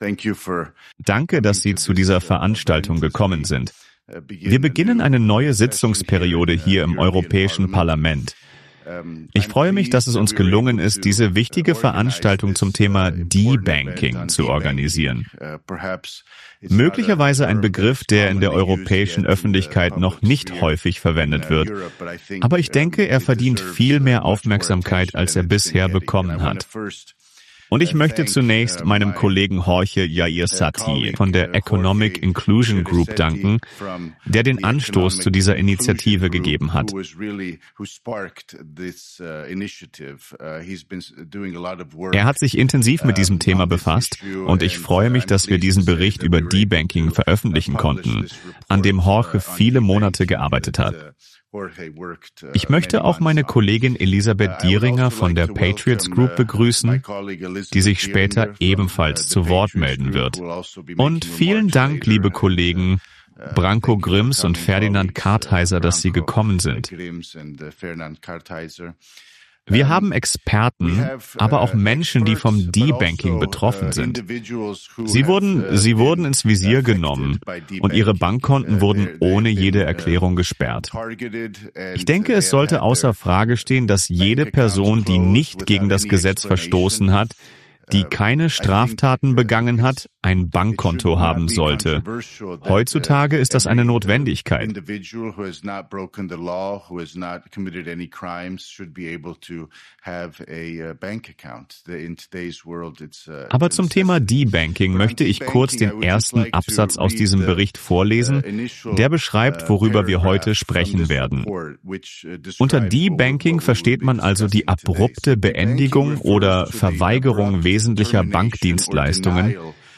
Das war die Debanking-Konferenz im EU-Parlament in Brüssel.